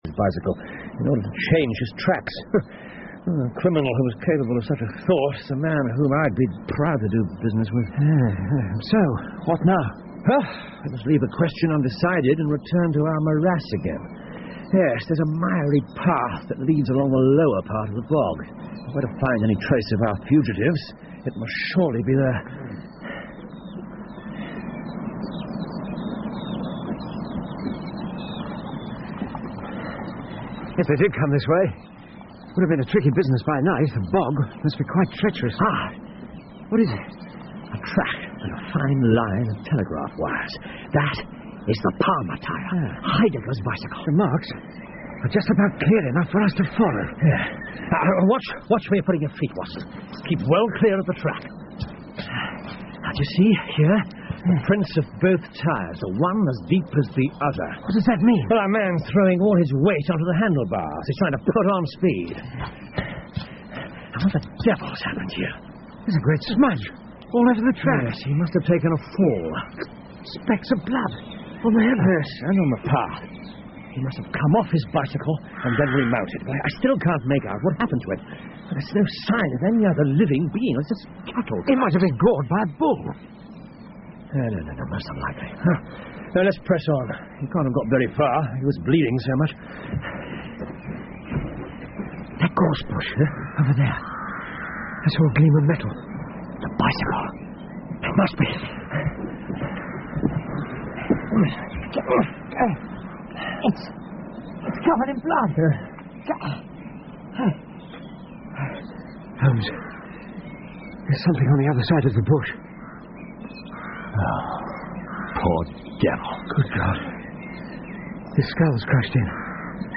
在线英语听力室福尔摩斯广播剧 The Priory School 5的听力文件下载,英语有声读物,英文广播剧-在线英语听力室